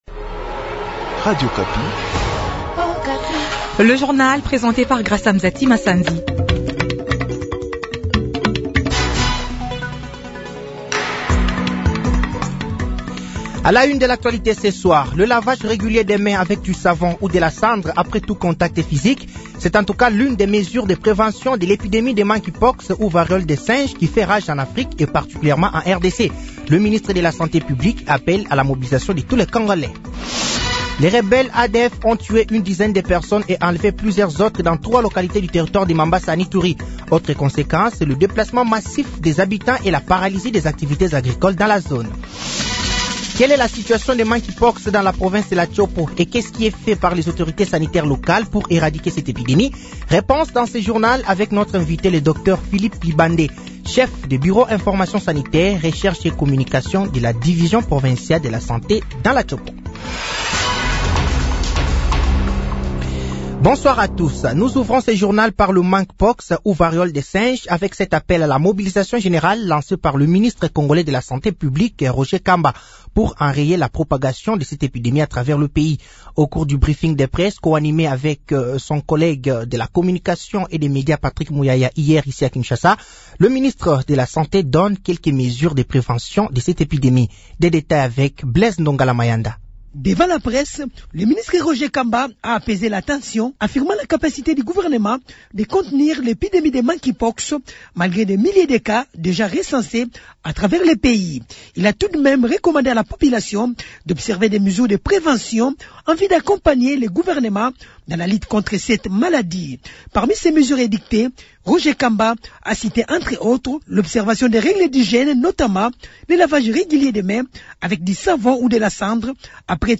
Journal français de 18h de ce vendredi 16 août 2024